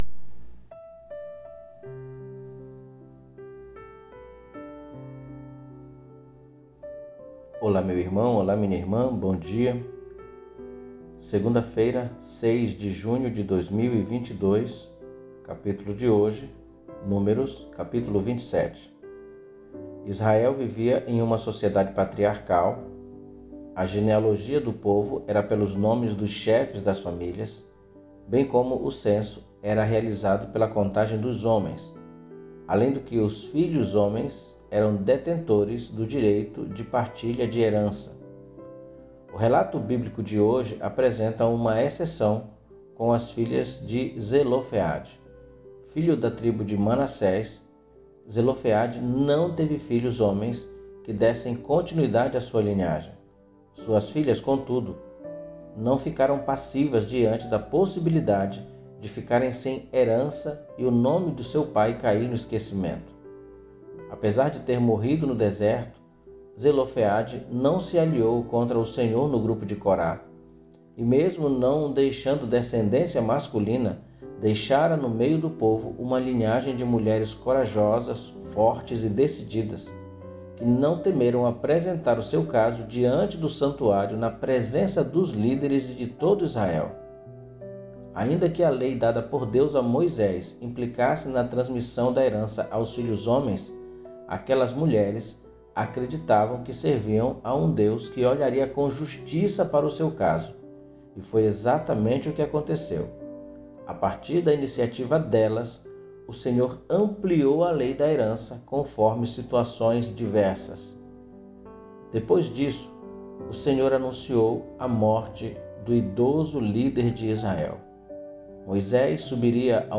PODCAST DE MEDITAÇÃO BÍBLICA DE HOJE